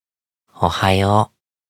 Chat Voice Files
Speaker Belphegor